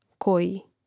kòjī koji